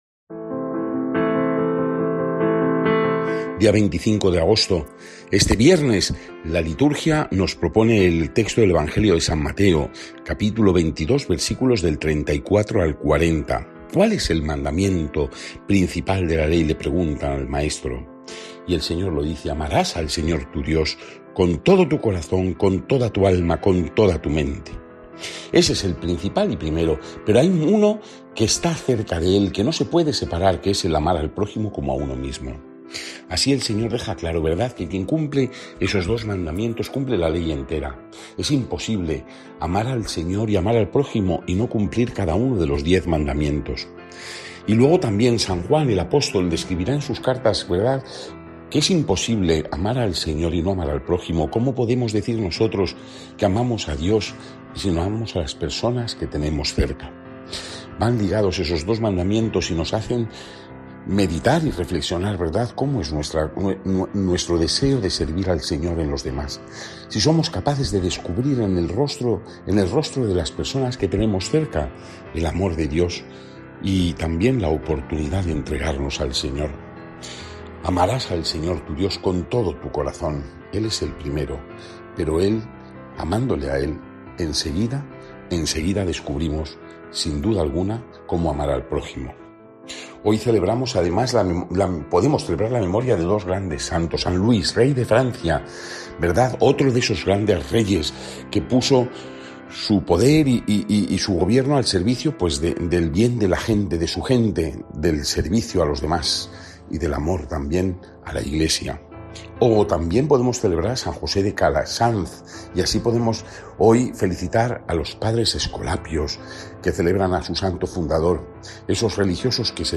Evangelio según san Mateo (22,34-40) y comentario